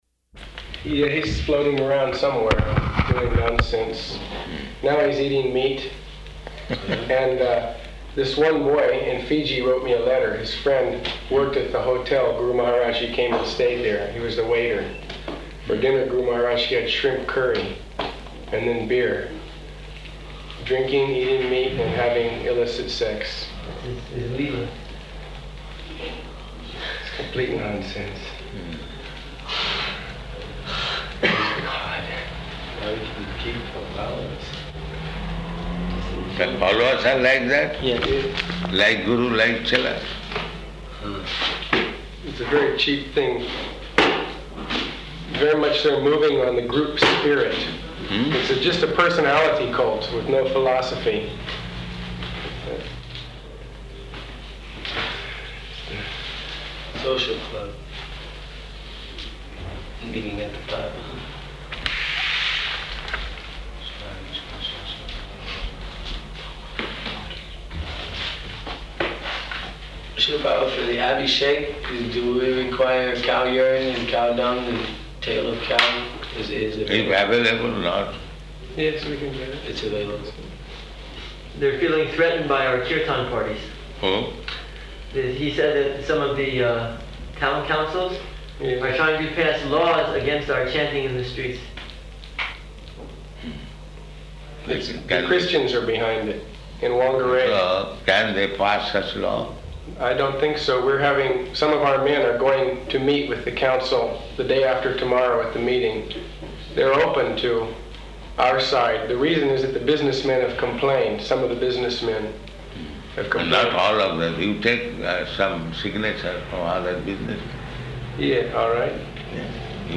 Room Conversation
Room Conversation --:-- --:-- Type: Conversation Dated: April 27th 1976 Location: Auckland, New Zealand Audio file: 760427R1.AUC.mp3 Devotee (1): Yeah, he's floating around somewhere doing nonsense.